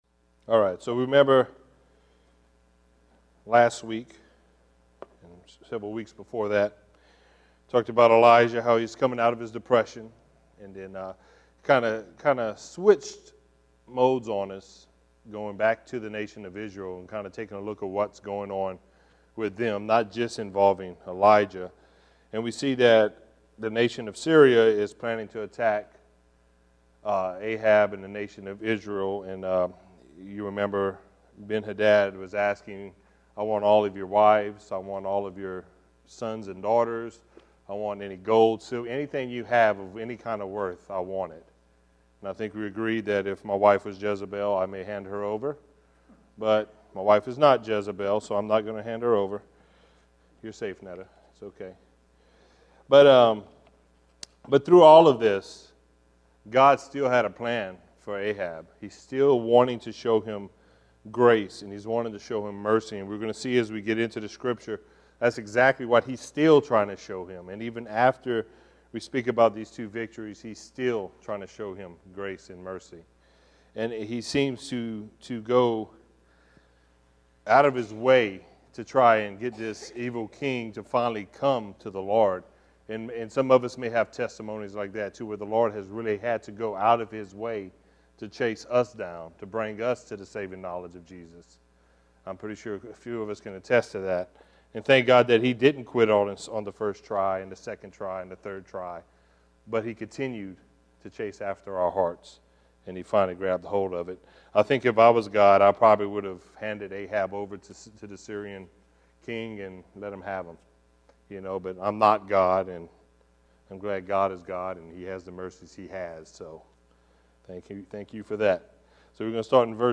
The book of 1 Kings is the sequel to 1 and 2 Samuel and begins by tracing Solomon’s rise to kingship after the death of David. The story begins with a united kingdom, but ends in a nation divided into 2 kingdoms. Join us for this verse by verse study